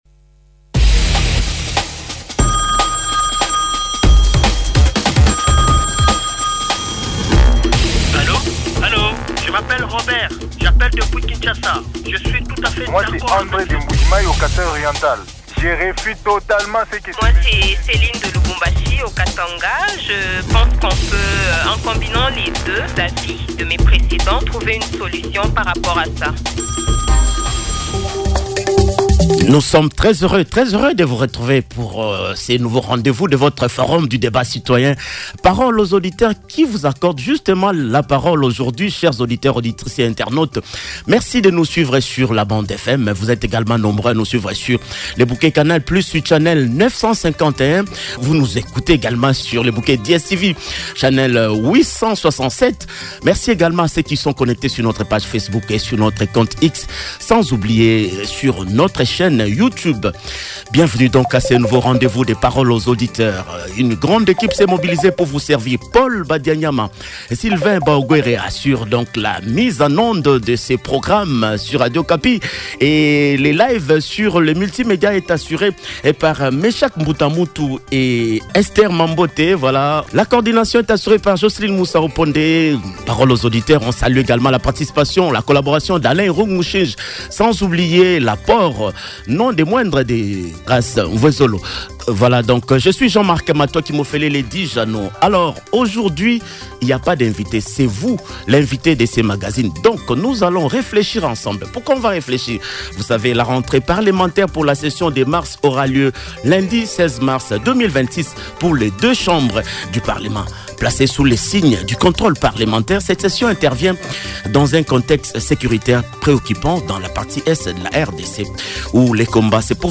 C’est l’essentiel des avis des auditeurs, auditrices et internautes exprimés au cours de l’émission Paroles aux auditeurs qui s’est penchée sur la rentrée parlementaire du lundi 16 mars 2026 pour les deux chambres du parlement.